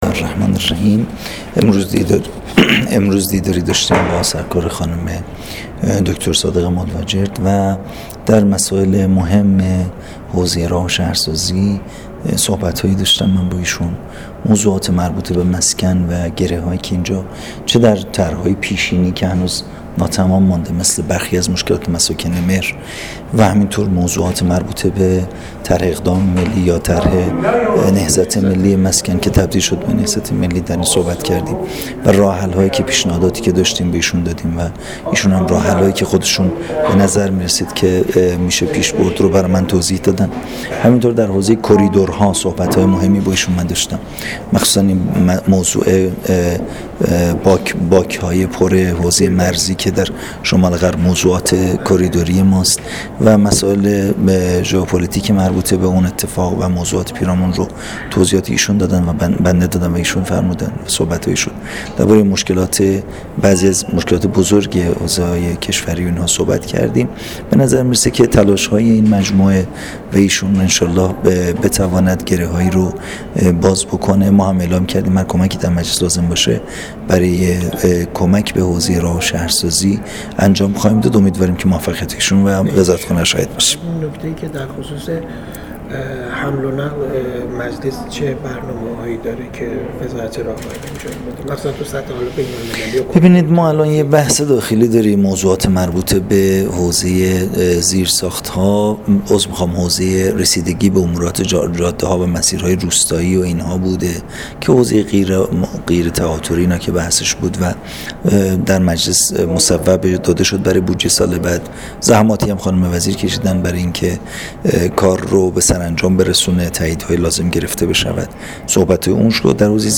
گزارش رادیو اینترنتی از آخرین وضعیت ترافیکی جاده‌ها تا ساعت ۹ بیستم دی؛